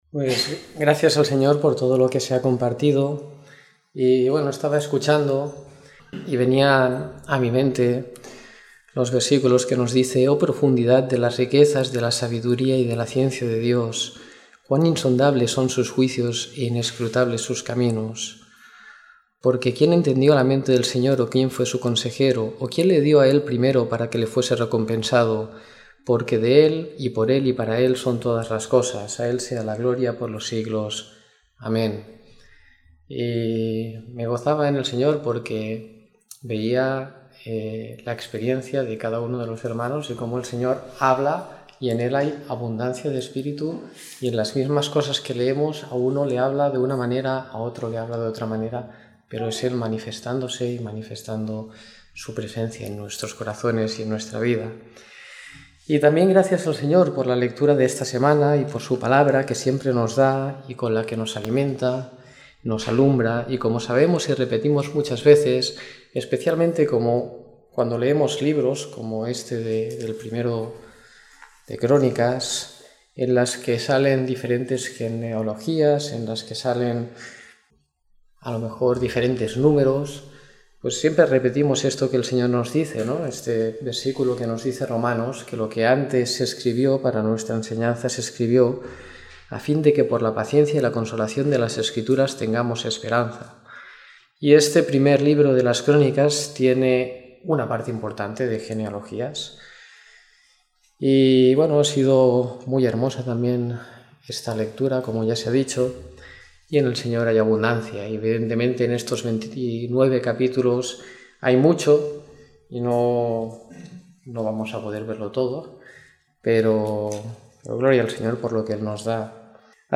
Comentario en el libro de 1º Crónicas siguiendo la lectura programada para cada semana del año que tenemos en la congregación en Sant Pere de Ribes.